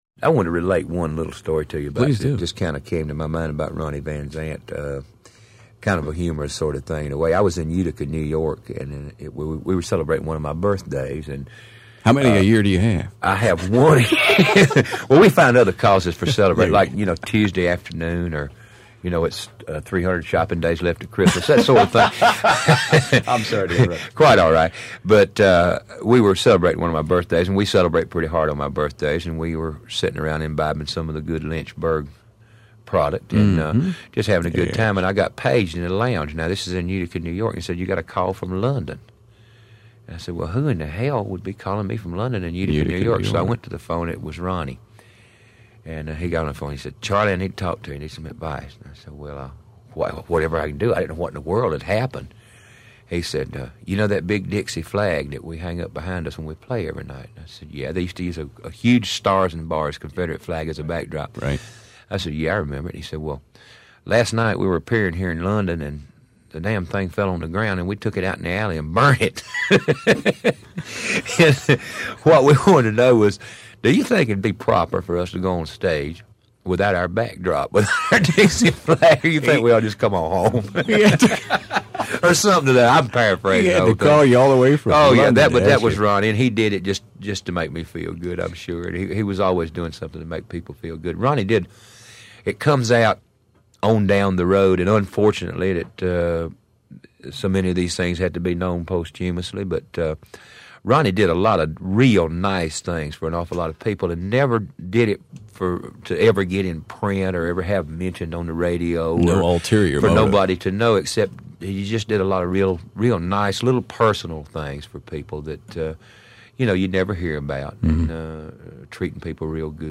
Every year while I worked in Memphis following the October 20, 1977 plane crash which killed the road manager, pilots, and three members of Lynyrd Skynyrd including their lead singer and songwriter Ronnie Van Zant, the father figure of the whole Southern Rock family, Charlie Daniels, would visit or call Memphis rock radio station ROCK 103 on that date to pay his fond respects.
charlie-daniels-tribute-2-ronnie-van-zant.mp3